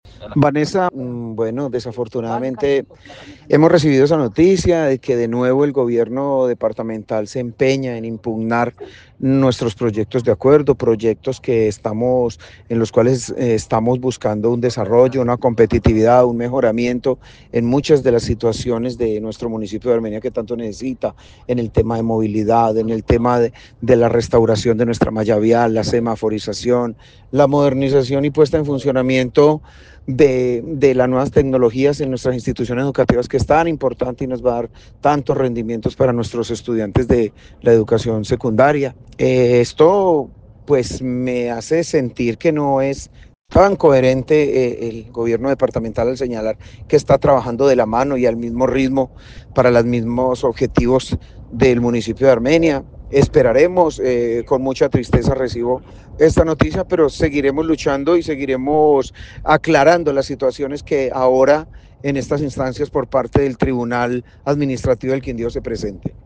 Alcalde de Armenia, James Padilla